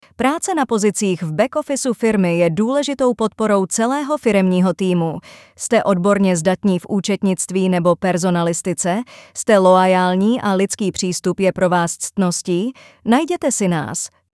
Práce v oddělení back-office (audiospot)
Audiospot_Prace_oddeleni_backoffice.mp3